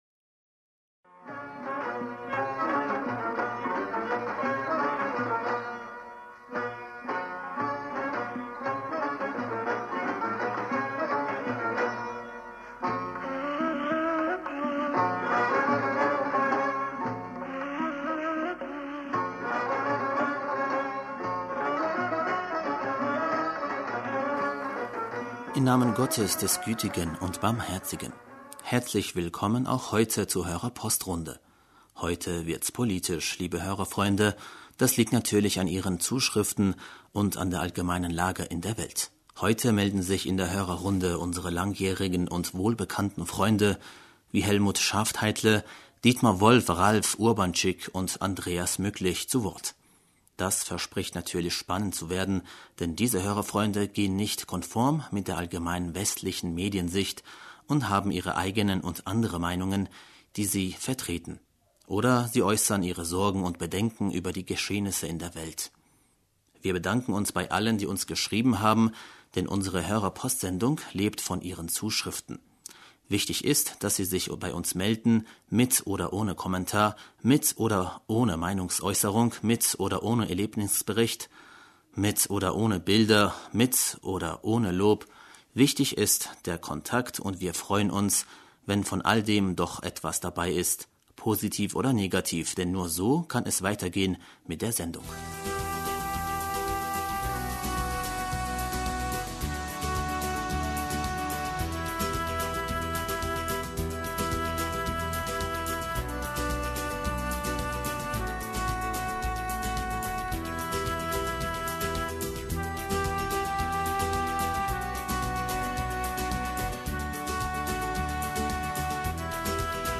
Hörerpostsendung am 26. August 2018 - Bismillaher rahmaner rahim - Herzlich willkommen auch heute zur Hörerpostrunde.